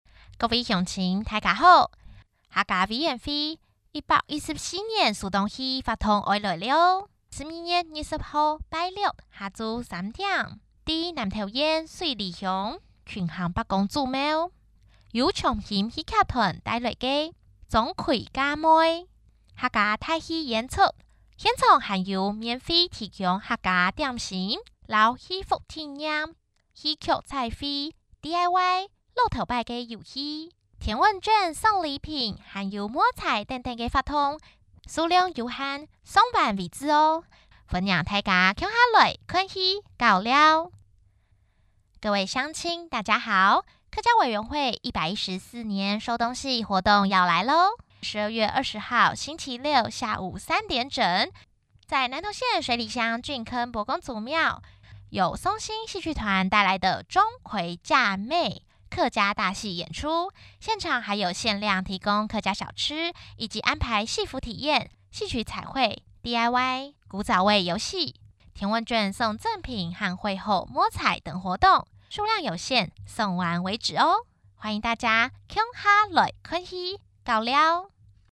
女性配音員 國語配音 客語配音員